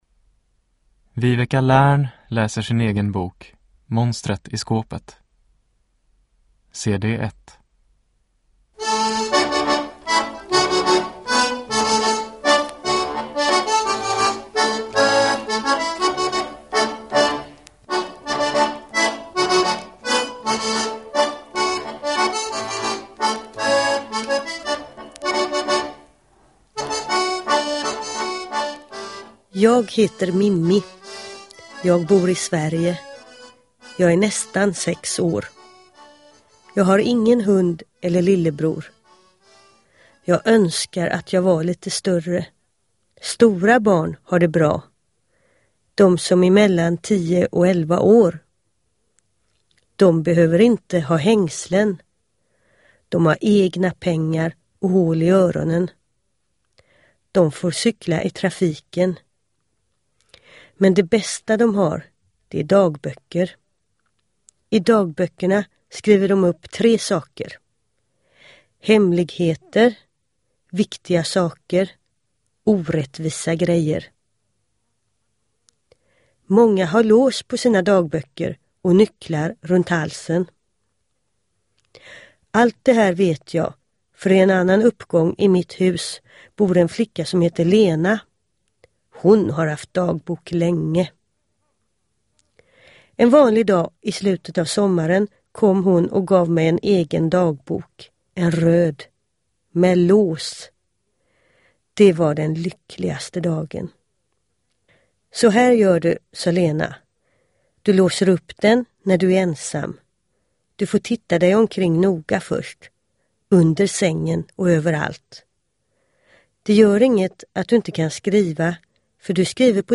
Monstret i skåpet – Ljudbok
Uppläsare: Viveca Lärn